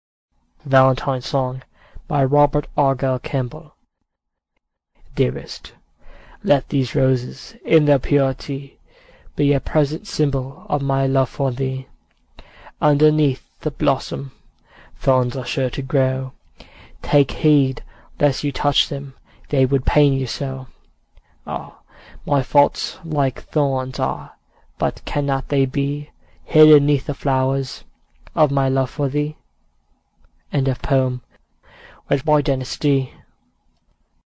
Valentine Song – A Poem with an MP3 Recording